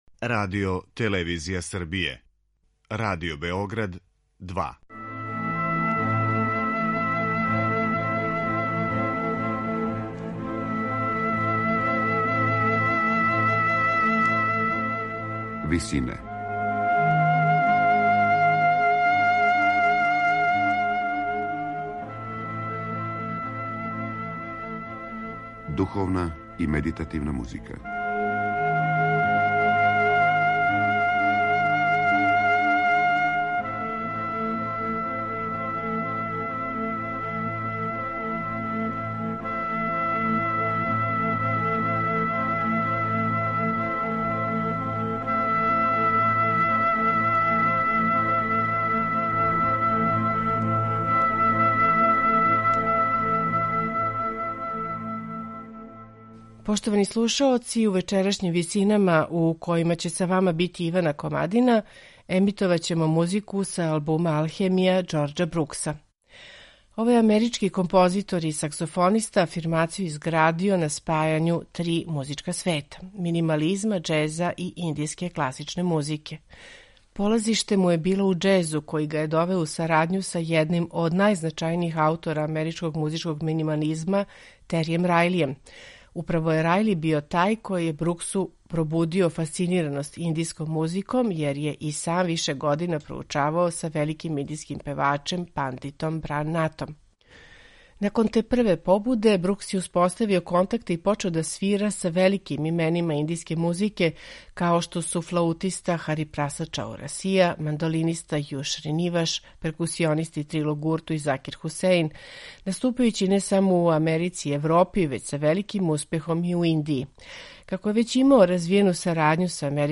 медитативне и духовне композиције
саксофониста